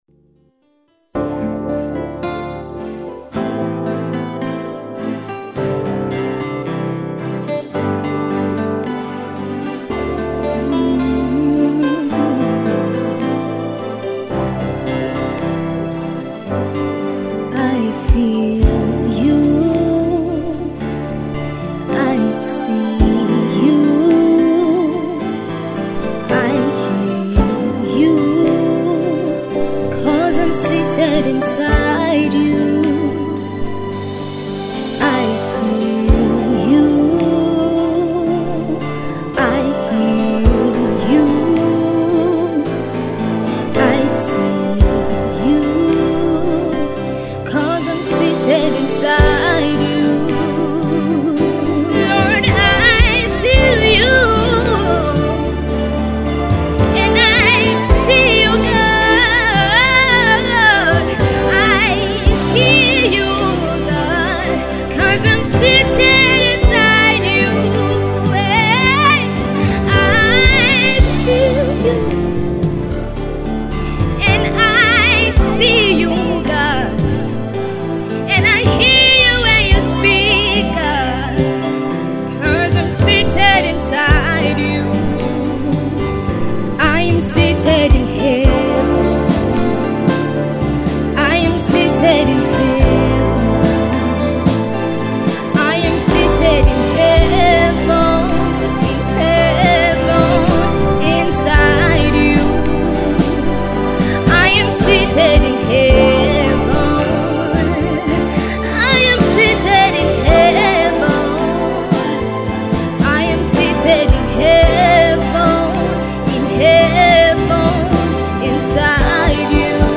singer and songwriter